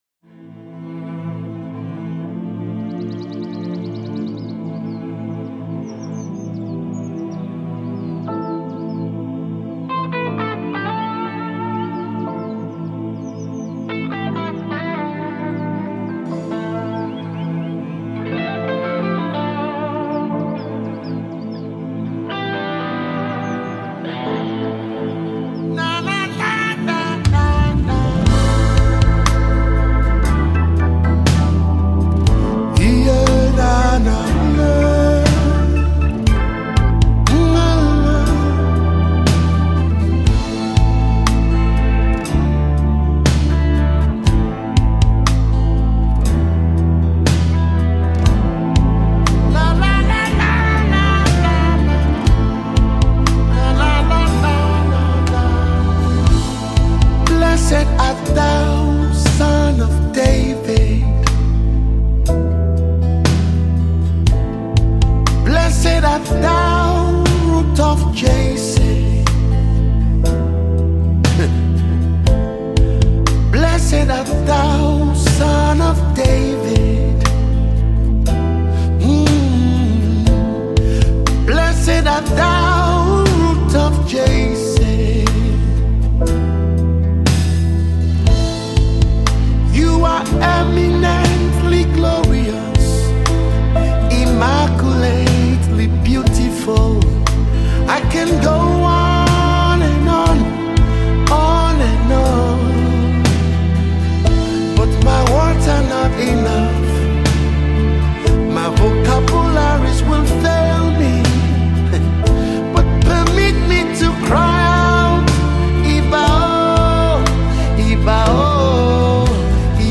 calm yet anointed delivery